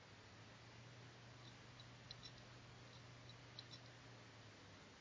hat leider auch nichts gebracht, schade... ich habe mir mal gedacht ich mach mal eine audioaufnahme von dem festplattengeräusch wenn ich sie am strom anschließe...vlt weiß ja jemand was damit anzufangen ?